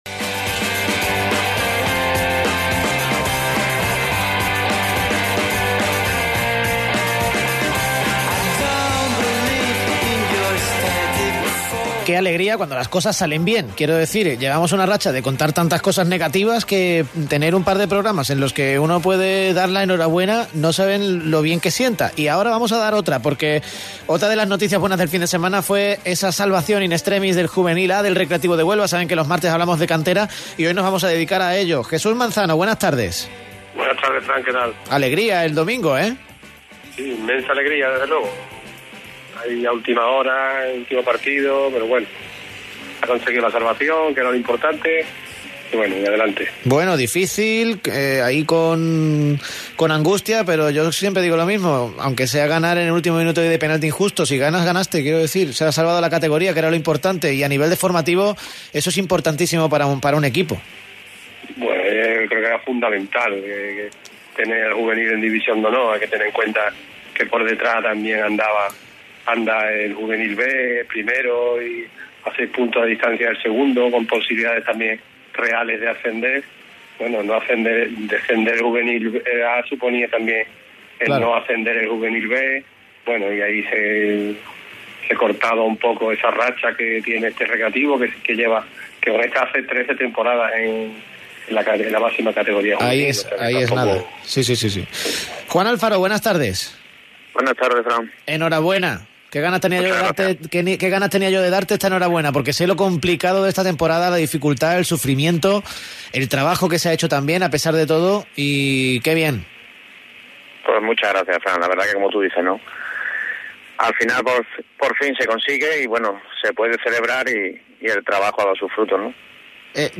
Cantera / Radio